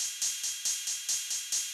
K-4 Ride.wav